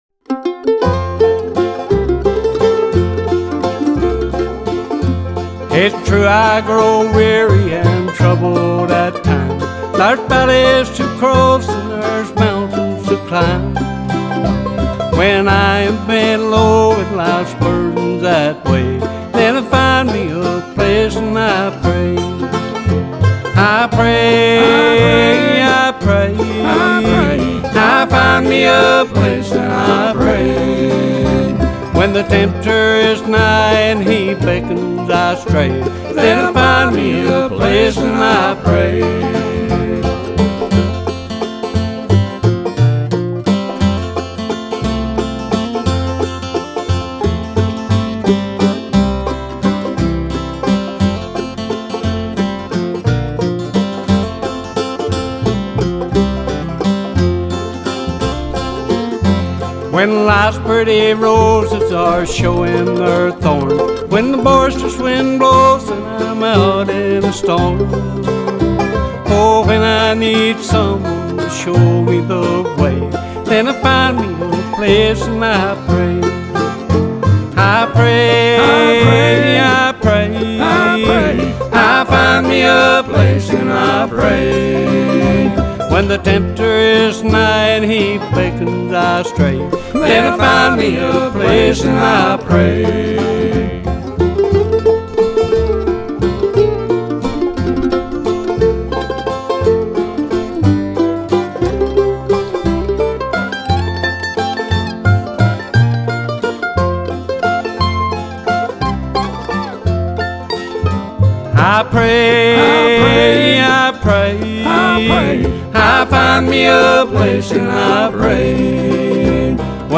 This is American traditional music at its very best.